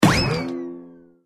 wood_damage_03.ogg